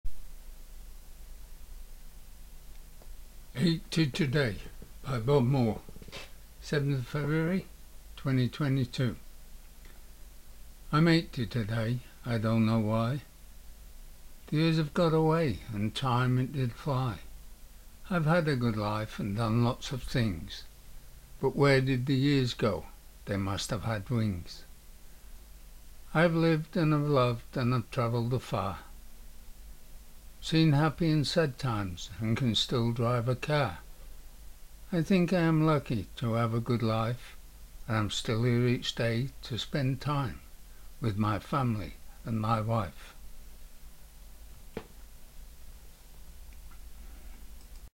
You read poetry like a professional.